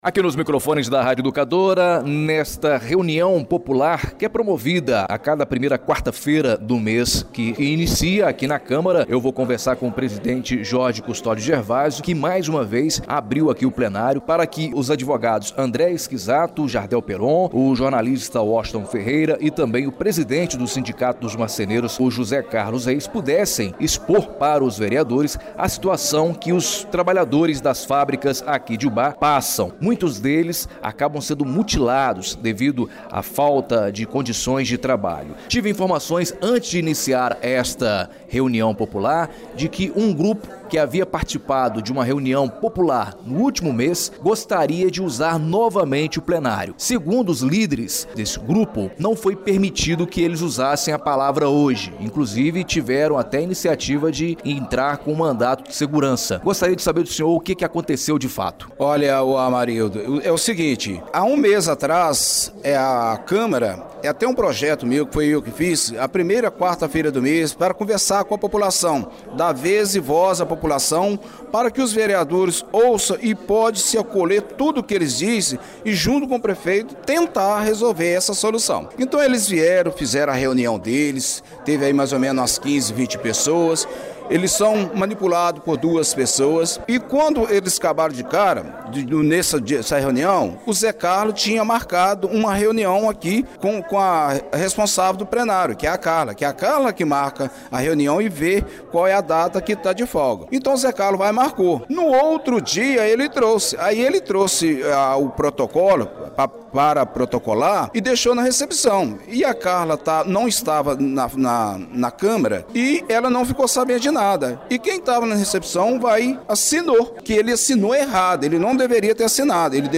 Sindicato dos Marceneiros de Ubá realiza reunião na Câmara Municipal e alerta sobre acidentes nas Fábricas de Móveis da cidade
A reunião teve a participação dos membros da Comissão Permanente de Agricultura, Comércio, indústria e Meio Ambiente.